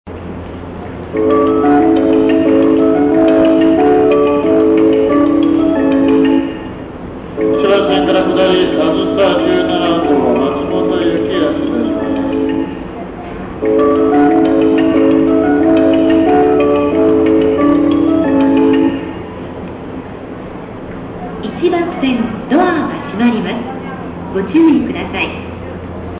甲府駅１番線中央本線の甲府駅のメロディ＆発車放送です。